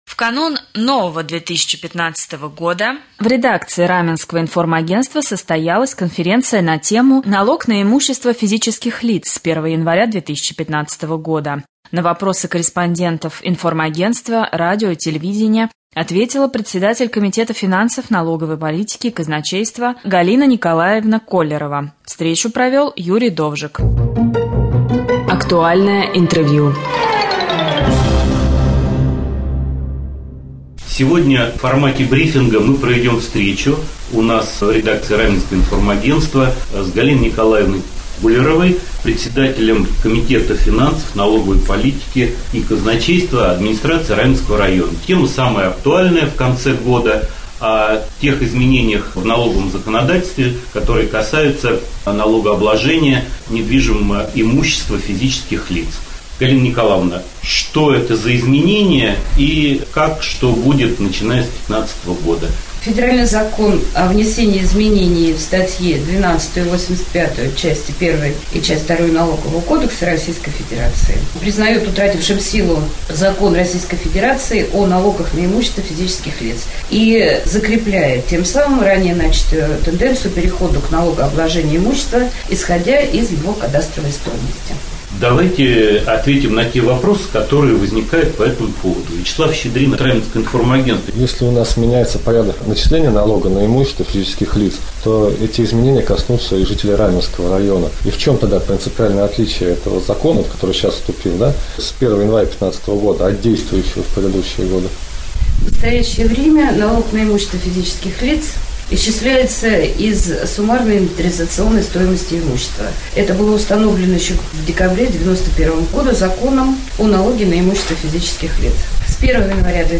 В Раменском информагентстве в канун нового года состоялся круглый стол на тему: Налог на имущество физических лиц с 1 января 2015 года . Ситуацию разъяснила Председатель комитета финансов, налоговой политики и казначейства администрации Раменского района Коллерова Галина Николаевна.
Рубрика «Актуальное интервью».